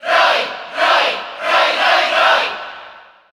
Category: Crowd cheers (SSBU) You cannot overwrite this file.
Roy_Cheer_Spanish_SSB4_SSBU.ogg